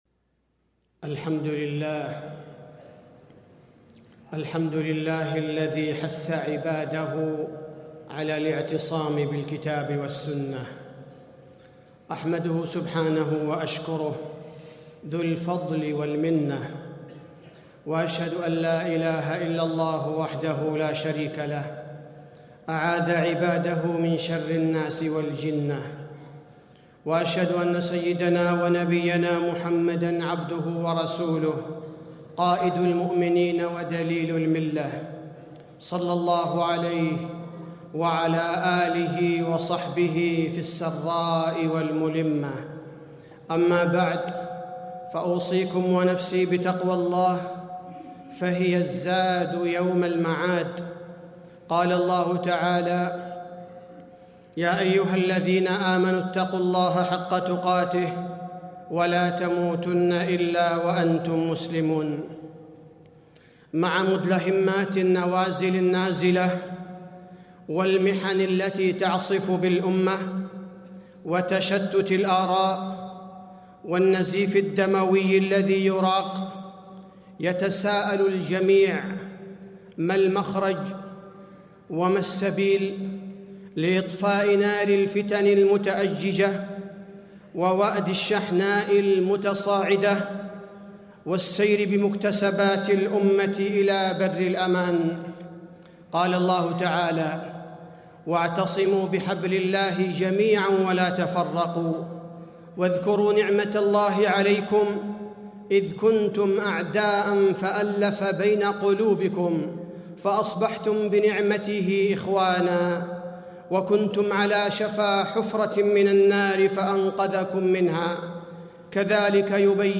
تاريخ النشر ١٦ شوال ١٤٣٤ هـ المكان: المسجد النبوي الشيخ: فضيلة الشيخ عبدالباري الثبيتي فضيلة الشيخ عبدالباري الثبيتي الاعتصام بالكتاب والسنة